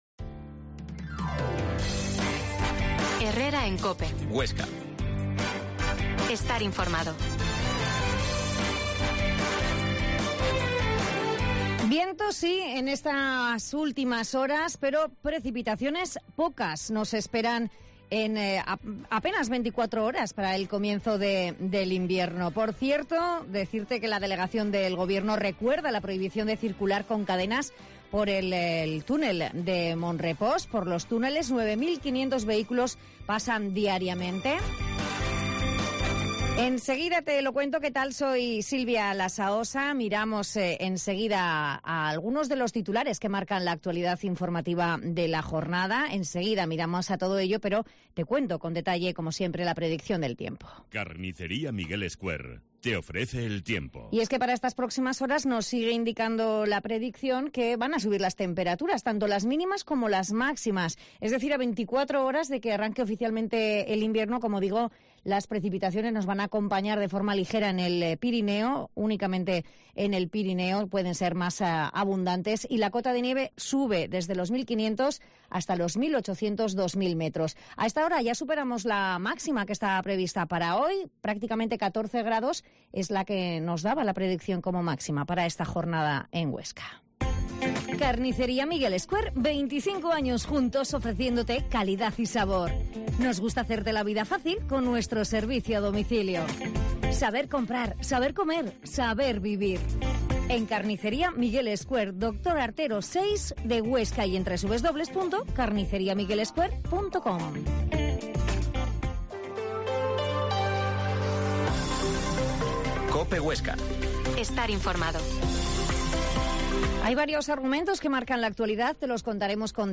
Herrera en COPE Huesca 12.50h Entrevista al obispo D.Julián Ruiz Martorell